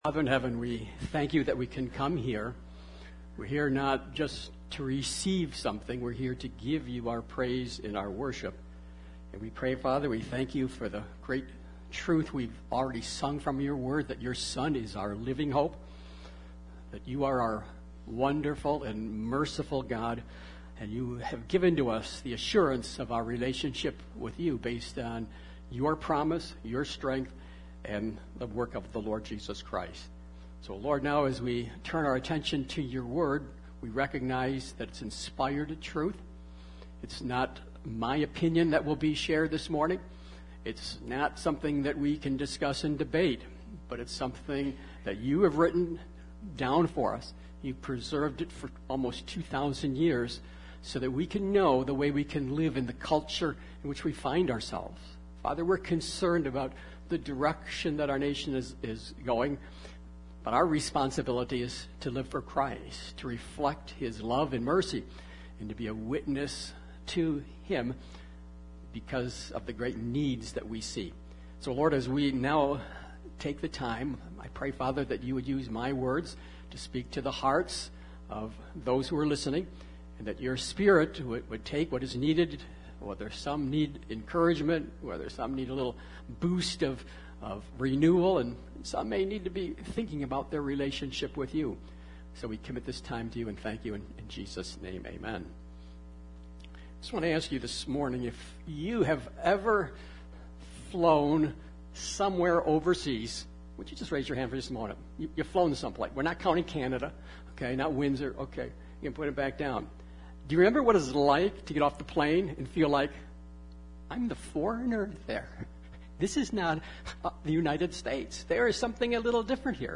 Nov 06, 2022 Reflecting Christ in Pagan America MP3 SUBSCRIBE on iTunes(Podcast) Notes Sermons in this Series 1 Peter 4:1-6 Thank You, Peter!